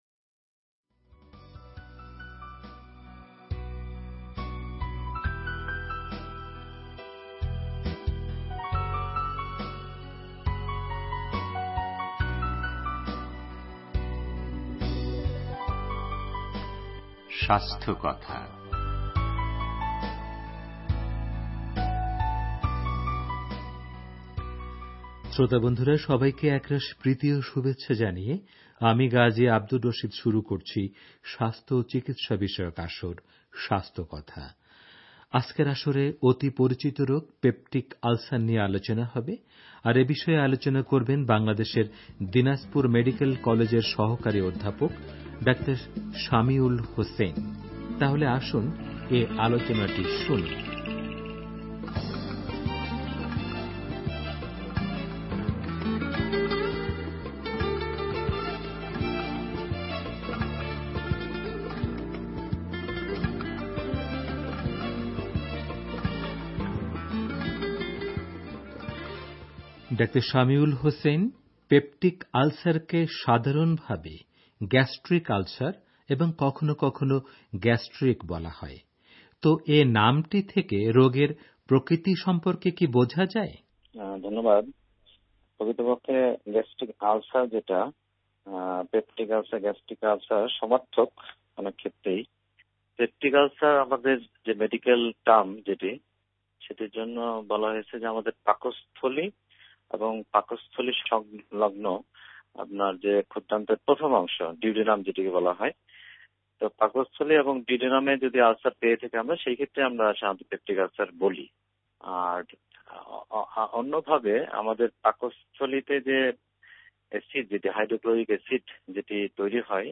রেডিও তেহরানের স্বাস্থ্য ও চিকিৎসা বিষয়ক অনুষ্ঠান স্বাস্থ্যকথা'র এ পর্বে অতি পরিচিত রোগ পেপটিস আলসার নিয়ে আলোচনা করেছেন